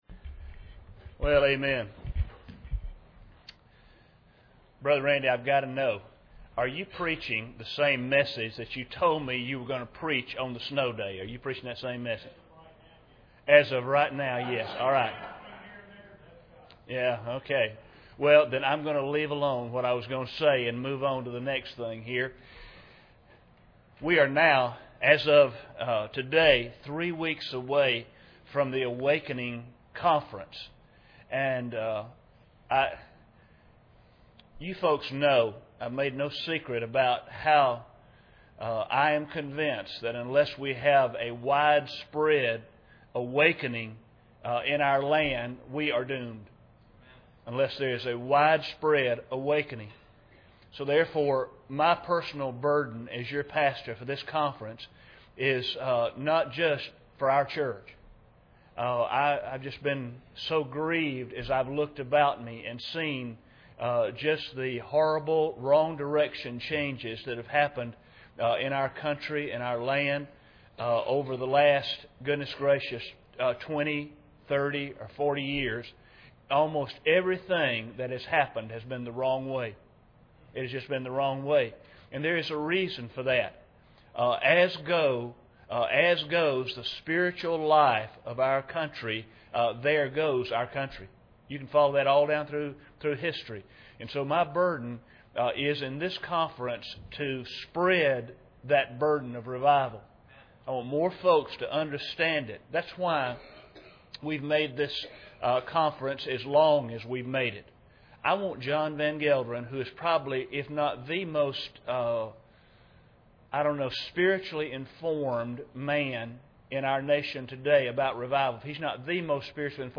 Please note, that due to technical difficulties, the last portion of the sermon is missing.
Service Type: Sunday Evening